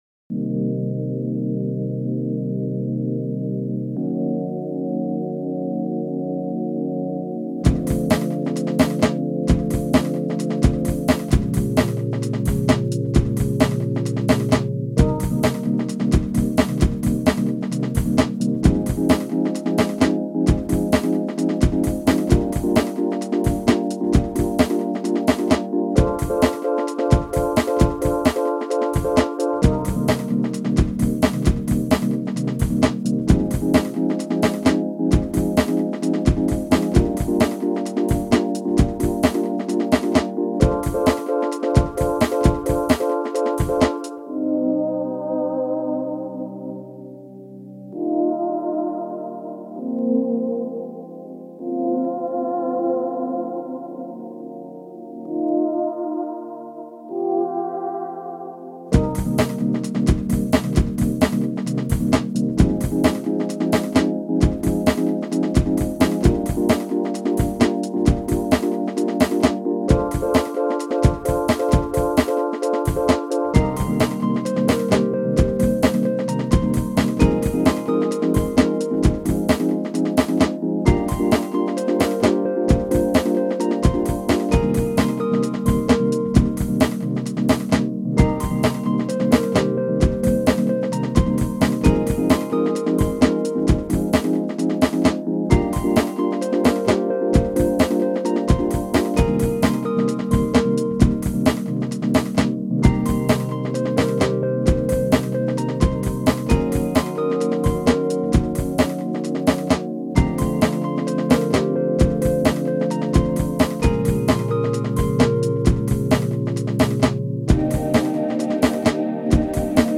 It’s all stock loops but makes for a lovely bit of nostalgia :content: